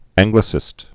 (ăngglĭ-sĭst)